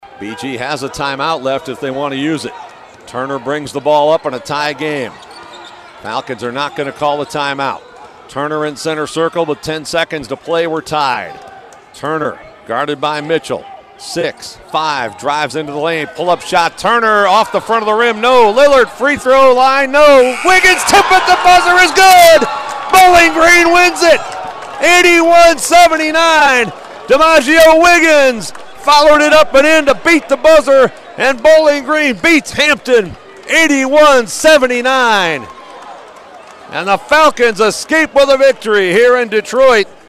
RADIO CALLS